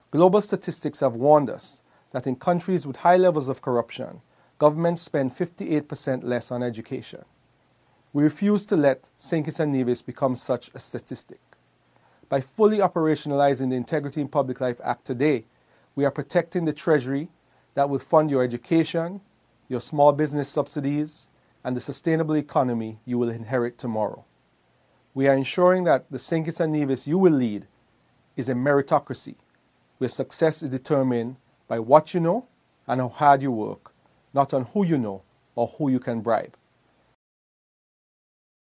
A central focus of the address was the theme: “Uniting with Youth against Corruption: Shaping Tomorrow’s Integrity.”